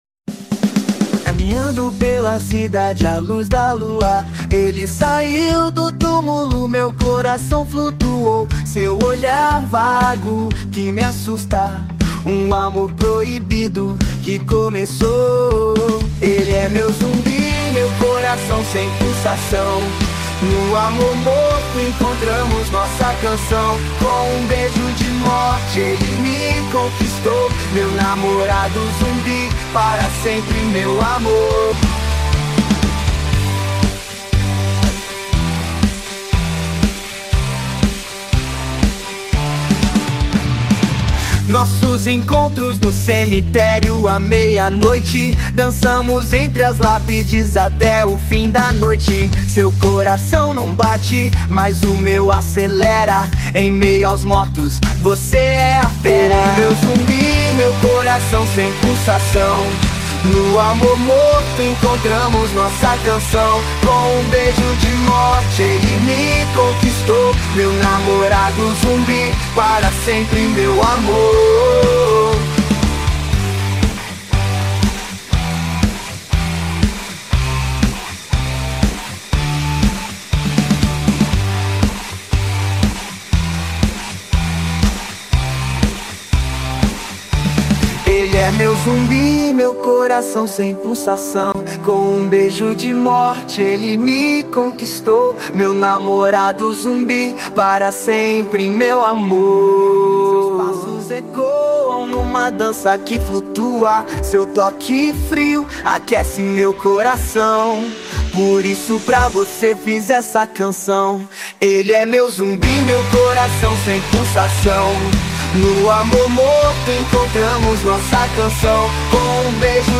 Gênero Funk.